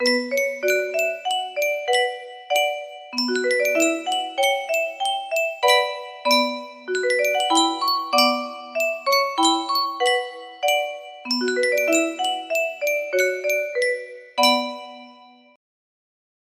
Yunsheng Carillon - Torna a Surriento 230Y music box melody
Full range 60